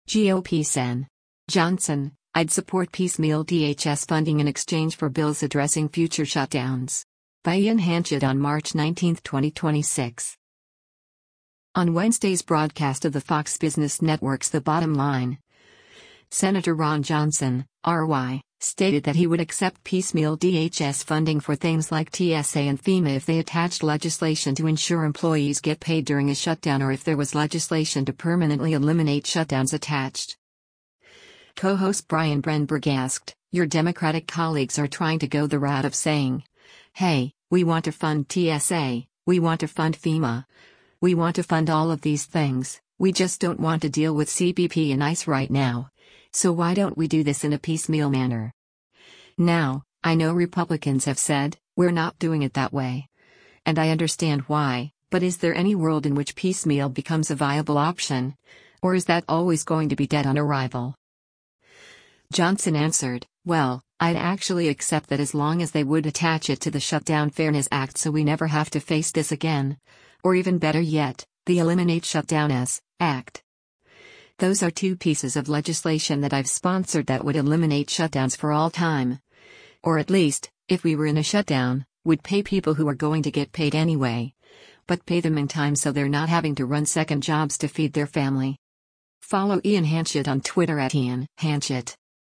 On Wednesday’s broadcast of the Fox Business Network’s “The Bottom Line,” Sen. Ron Johnson (R-WI) stated that he would accept piecemeal DHS funding for things like TSA and FEMA if they attached legislation to ensure employees get paid during a shutdown or if there was legislation to permanently eliminate shutdowns attached.